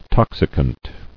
[tox·i·cant]